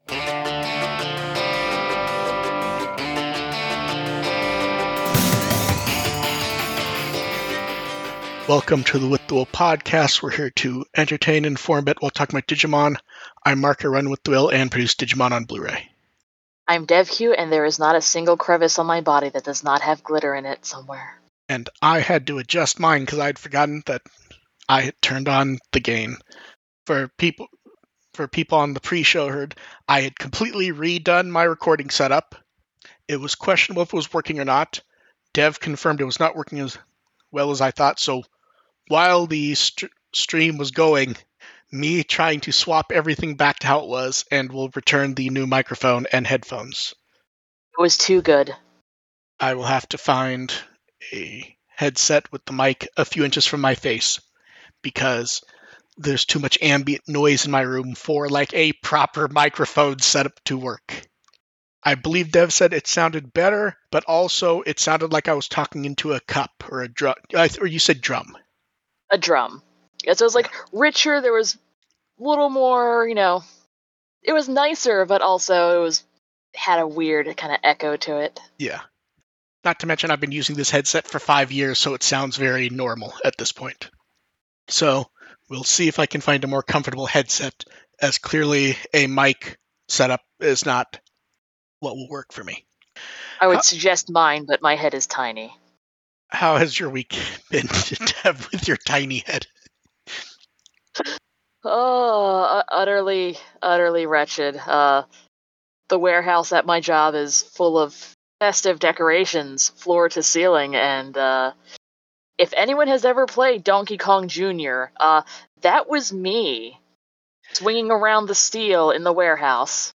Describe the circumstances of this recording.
The podcast audio is the livestream clipped out, with an intro and outtro added, along with some tweaking to try and improve audio quality.